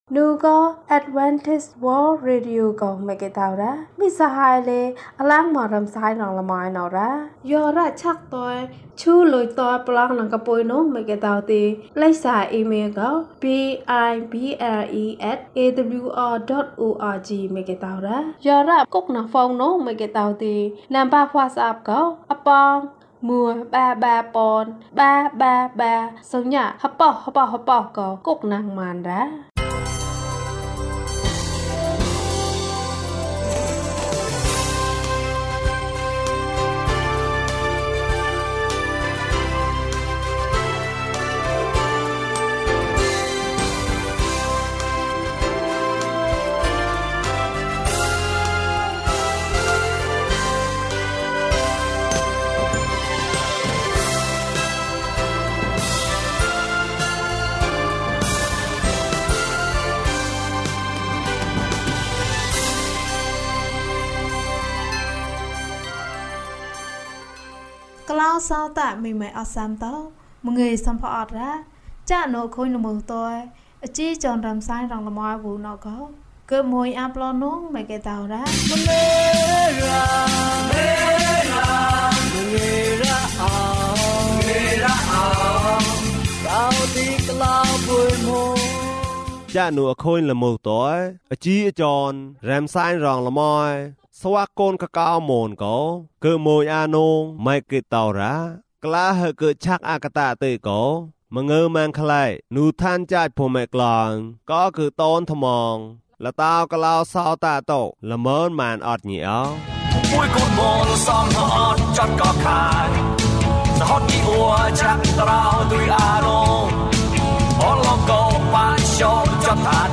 သင့်ခန္ဓာကိုယ်ကျန်းမာပါစေ။ ကျန်းမာခြင်းအကြောင်းအရာ။ ဓမ္မသီချင်း။ တရားဒေသနာ။